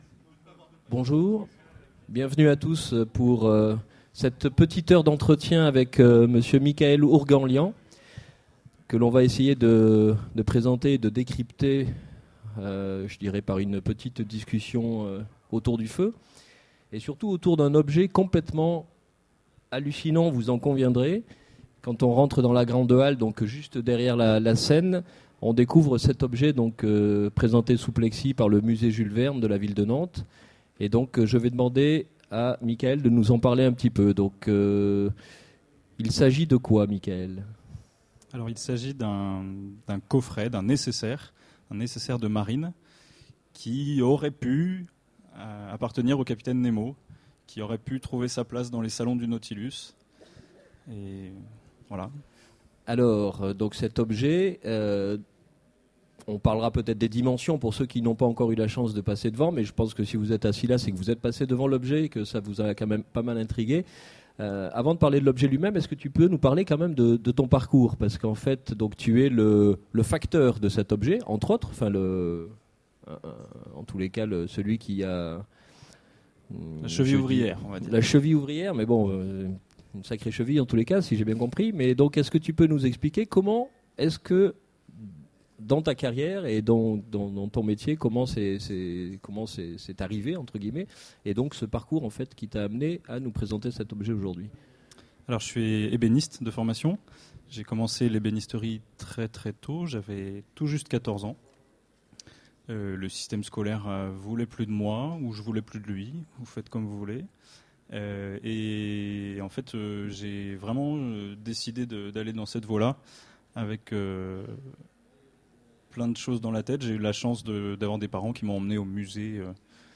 Utopiales 13 : Conférence Portrait et Nécessaire de marine du Capitaine Nemo